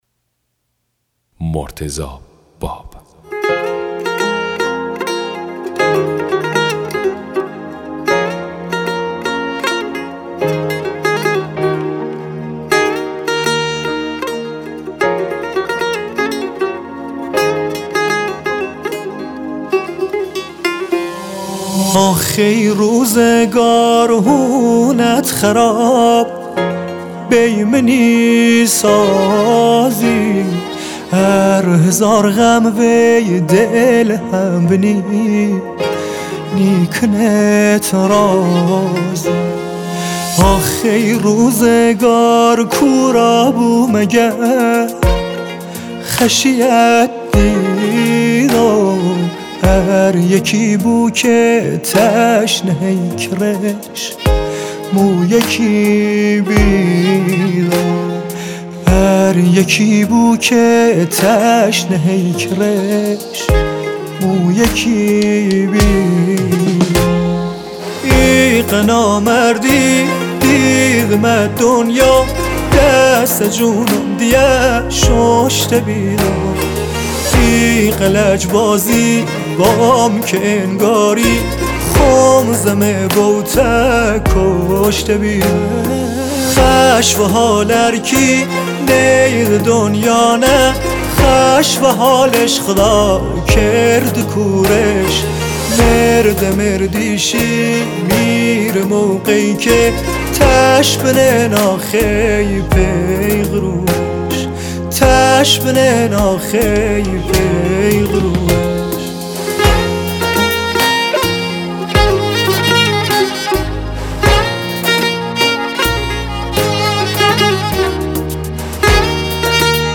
دانلود آهنگ محلی لری غمگین و پرطرفدار